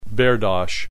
click this icon to hear the preceding term pronounced